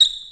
Squeek2b.wav